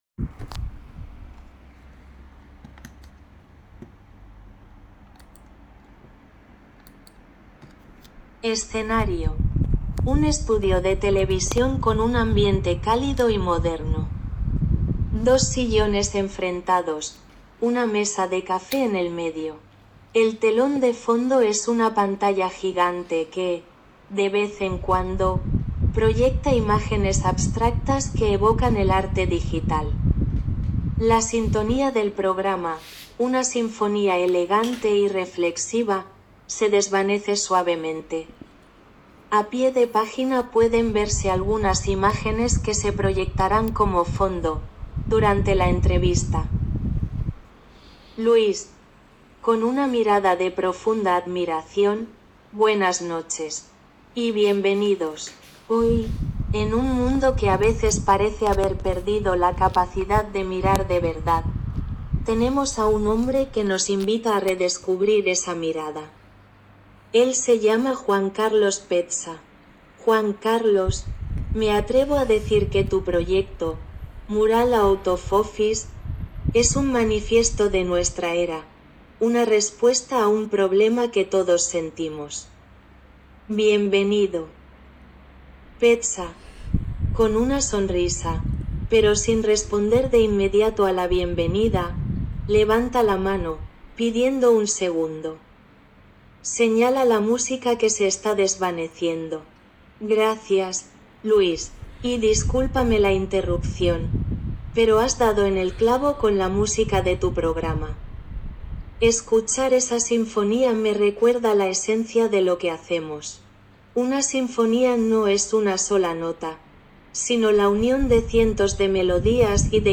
La entrevista en audio
La sintonía del programa, una sinfonía elegante y reflexiva, se desvanece suavemente.